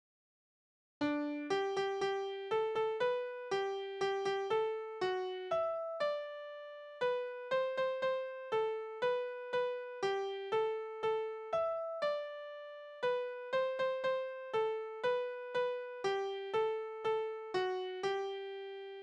Tonart: G-Dur
Taktart: 3/4
Tonumfang: große None